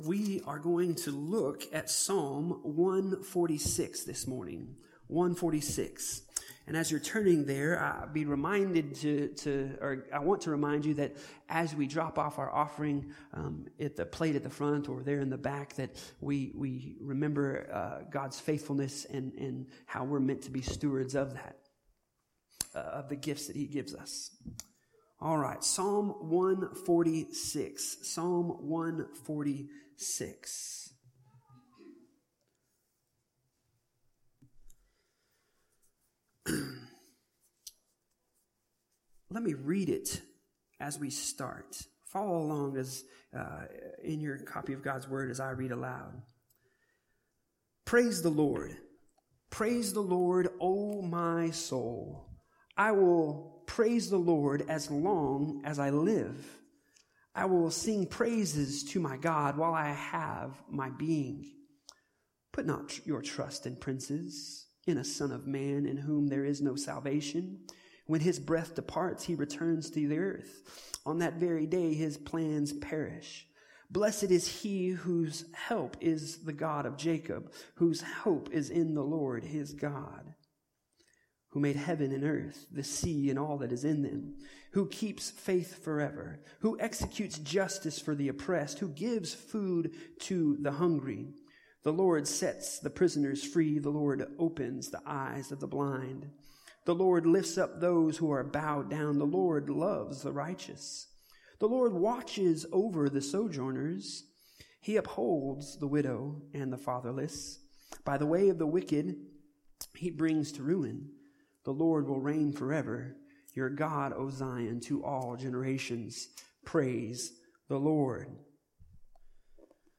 The audio recording is also from the in-person gathering – recorded live through our church sound system.